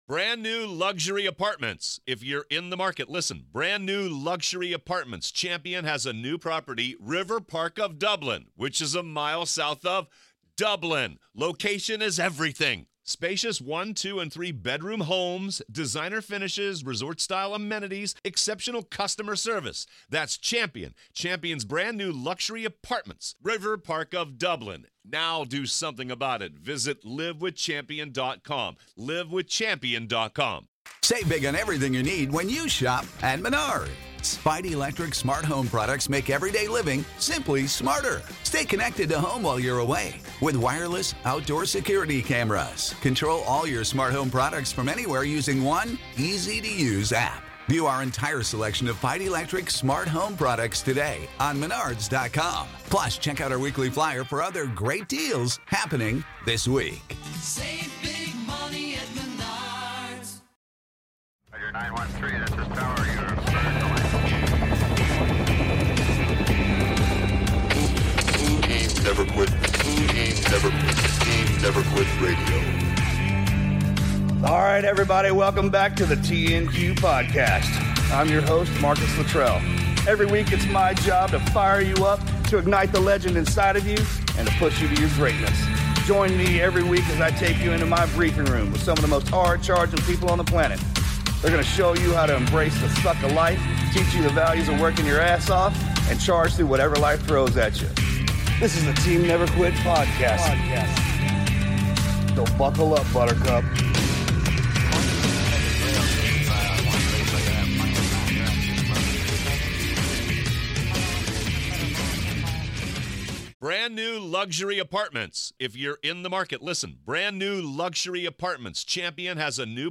in the studio today